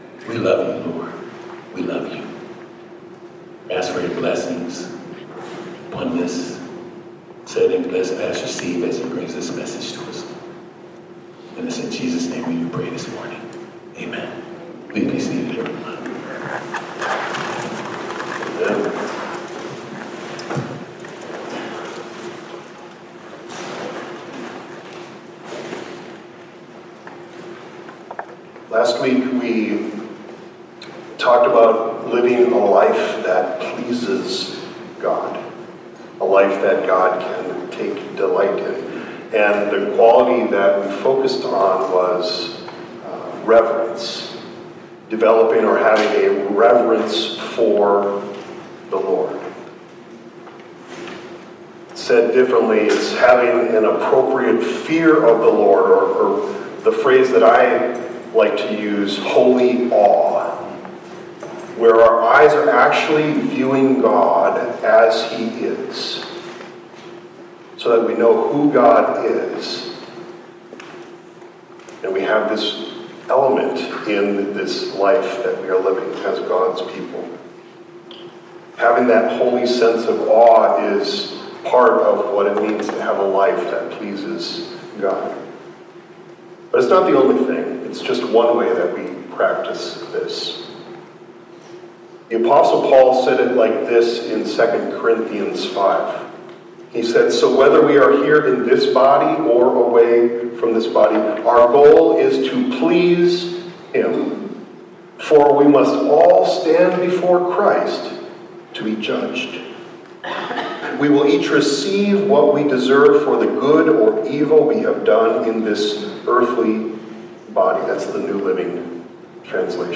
Download Posted in sermons